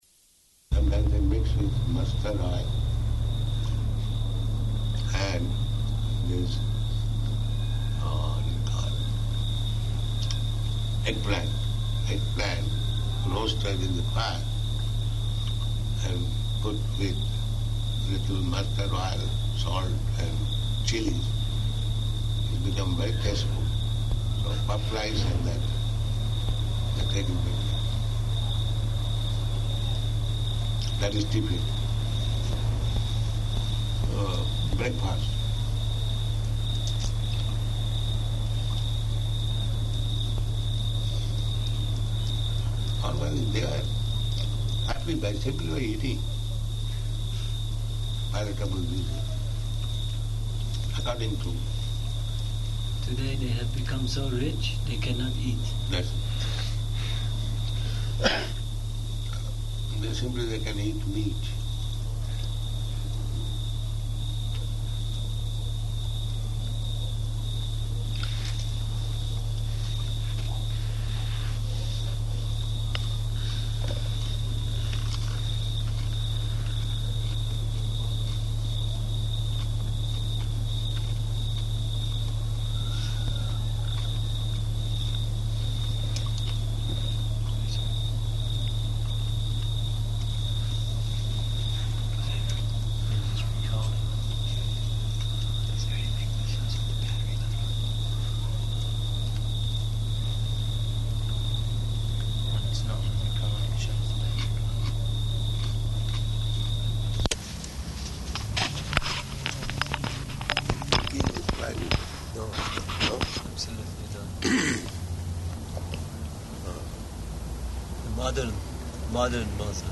Garden Conversation --:-- --:-- Type: Conversation Dated: August 9th 1976 Location: Tehran Audio file: 760809GC.TEH.mp3 Prabhupāda: And mix with mustard oil and this, what is called, eggplant.